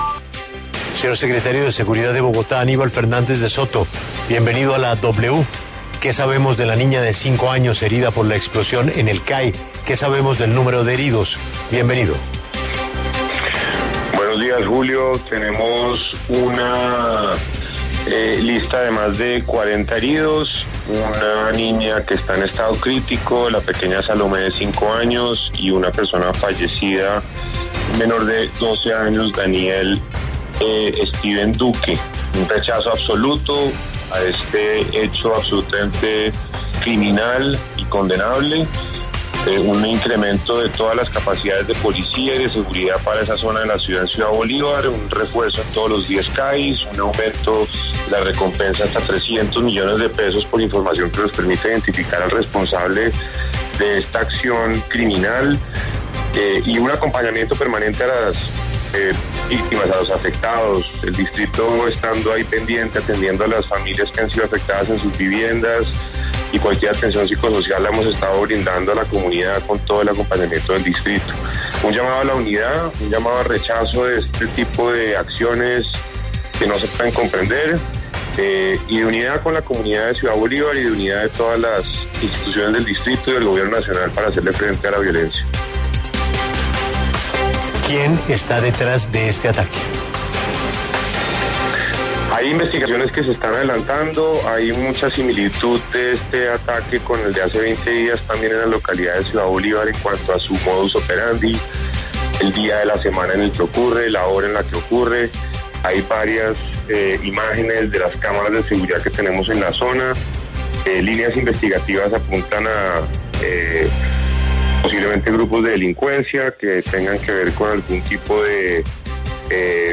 En diálogo con La W, Aníbal Fernández, secretario de Seguridad de Bogotá, hizo un balance sobre el atentado contra el CAI de Arborizadora Alta en el que dos menores de edad murieron.